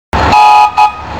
Horn & fløjter